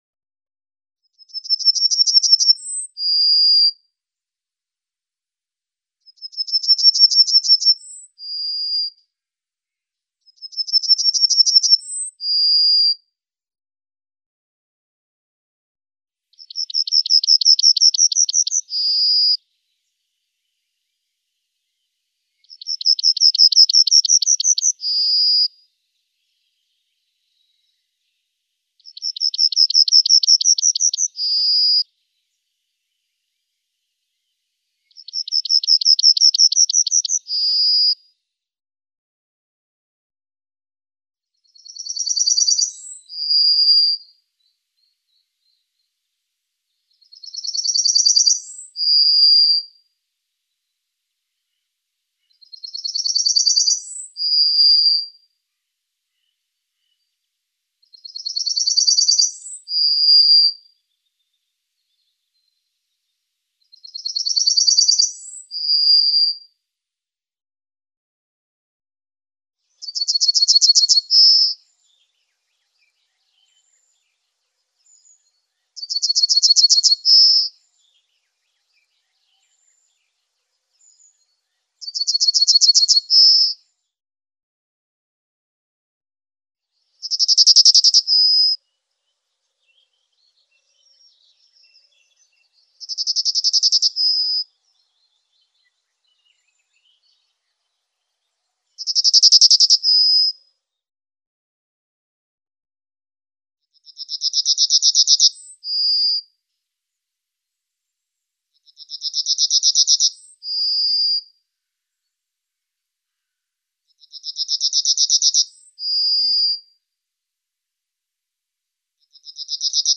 Emberiza_citrinella_Track_18.mp3